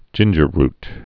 (jĭnjər-rt, -rt)